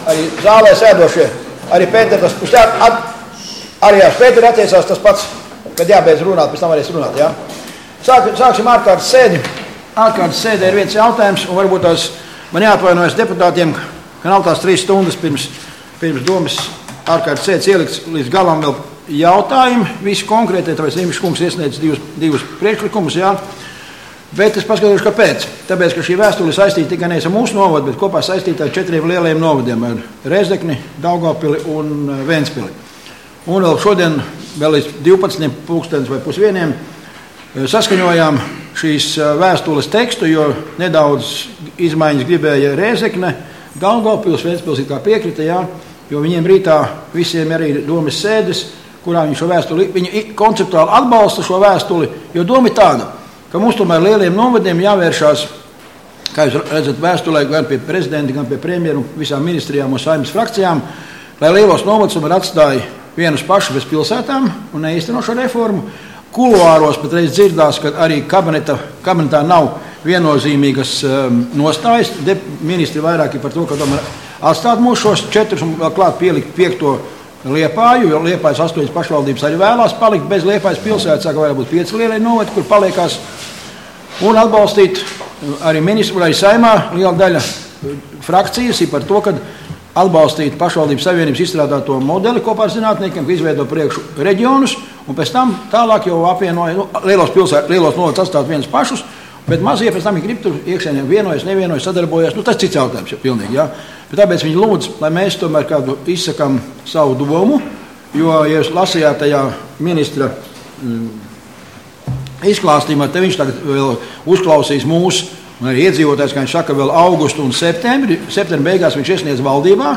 Domes ārkārtas sēde Nr. 14